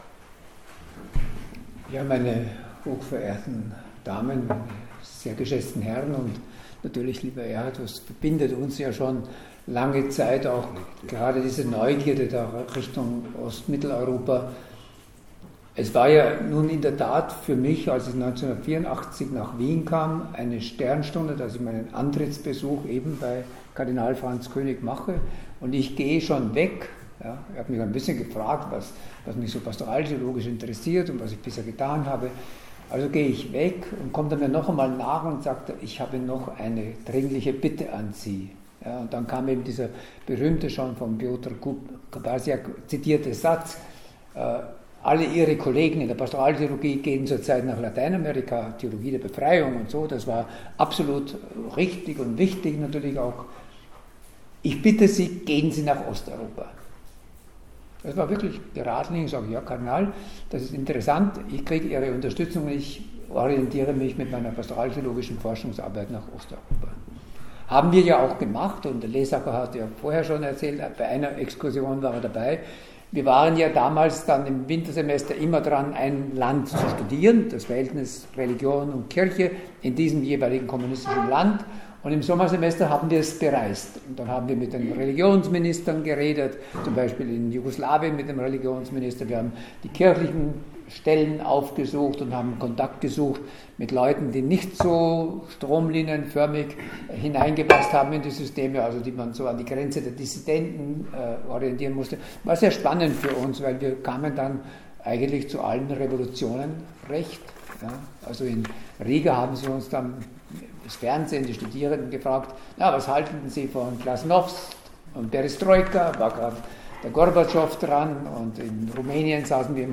Sie können seinen Impuls-Vortrag hier nachhören